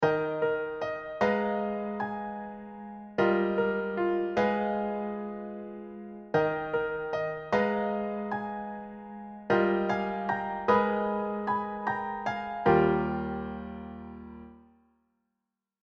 ほら、サブドミナント・マイナーさんはこうやってトニックさんずっと繰り返し一緒にいると
これはすっごくすっごくキュンとする音だよっ。